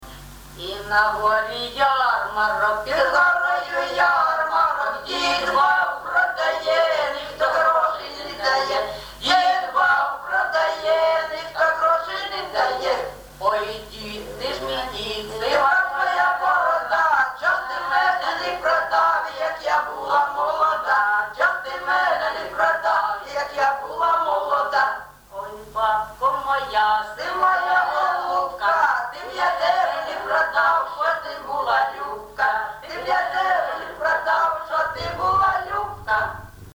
ЖанрЖартівливі
Місце записум. Єнакієве, Горлівський район, Донецька обл., Україна, Слобожанщина